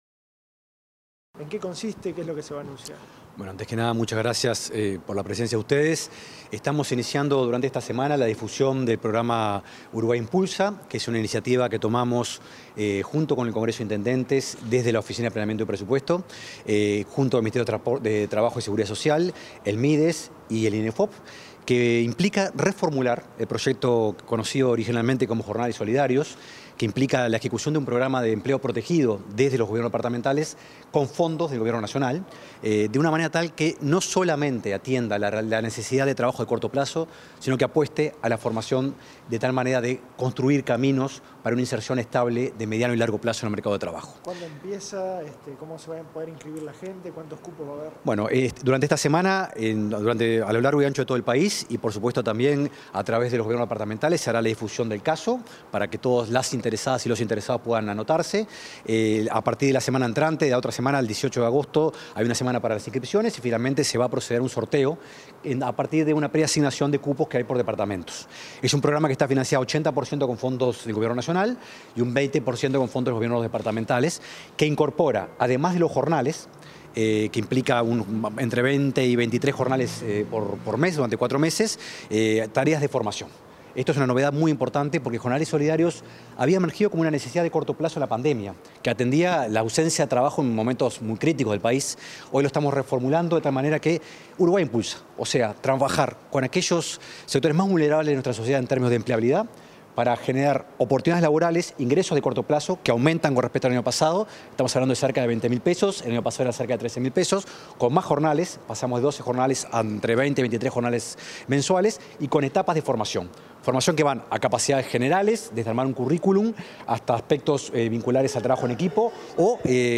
Declaraciones del director de OPP, Rodrigo Arim
Declaraciones del director de OPP, Rodrigo Arim 11/08/2025 Compartir Facebook X Copiar enlace WhatsApp LinkedIn El director de la Oficina de Planeamiento y Presupuesto (OPP), Rodrigo Arim, dialogó con los medios de comunicación sobre el comienzo del programa Uruguay Impulsa: Trabajo y Capacitación.